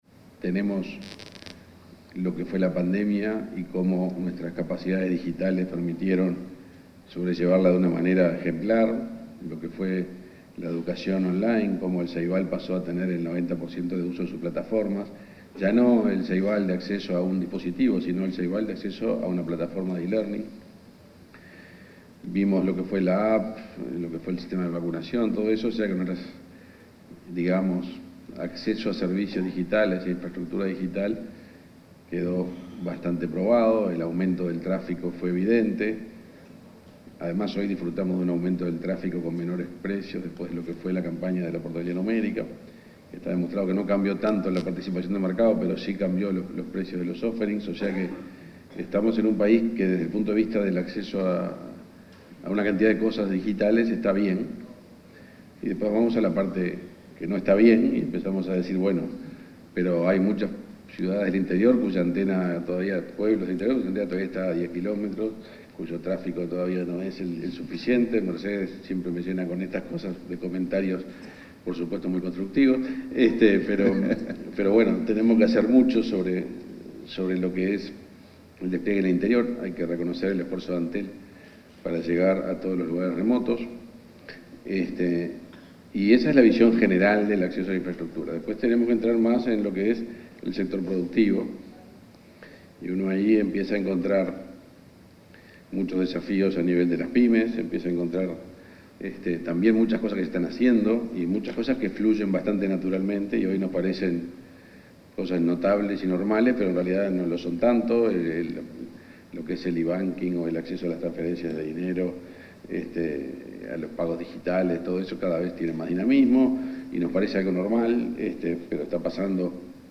Conferencia de prensa sobre el evento Agesic: Desafíos 2025
El ministro de Industria, Energía y Minería, Omar Paganini, participó, este 12 de octubre, en el panel Evolución de la Política Digital de Uruguay,